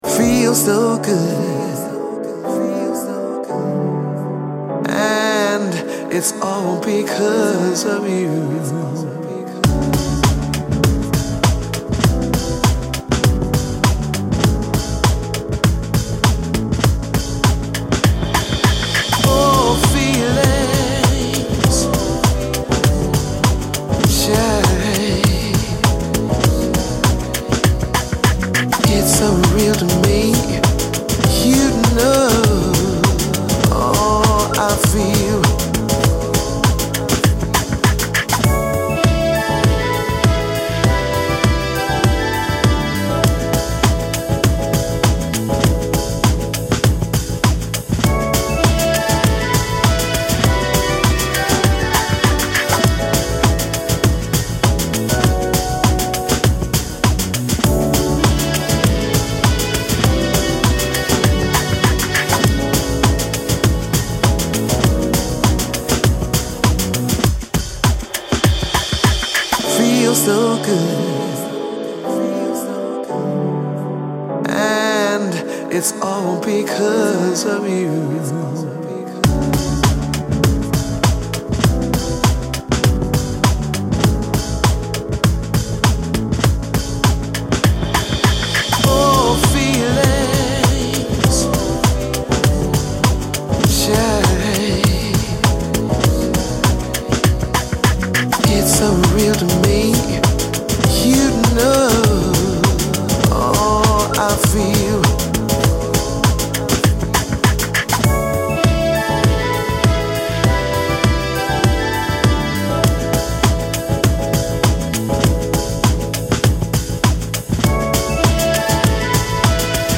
Du Groovy au Clubbing sont au rendez-vous de l’essentiel 10.